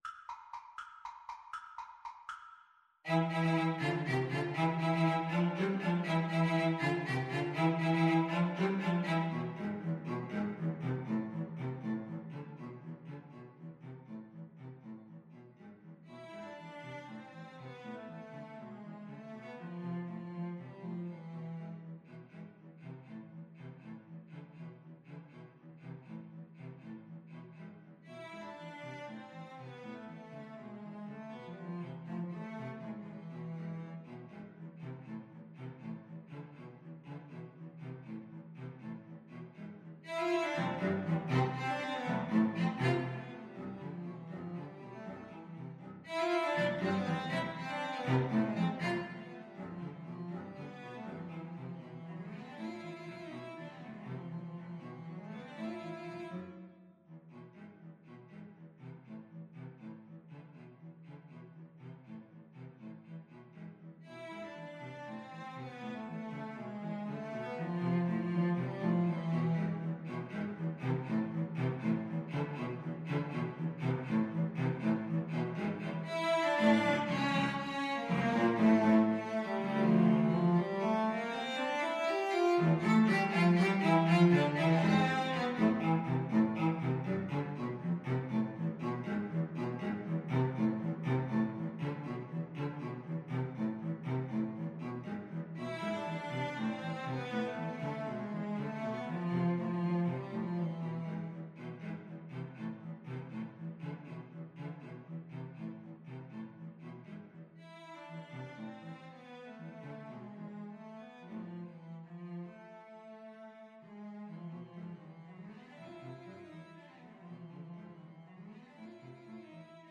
3/8 (View more 3/8 Music)
Allegro vivo (.=80) (View more music marked Allegro)
Classical (View more Classical Cello Trio Music)